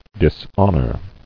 [dis·hon·or]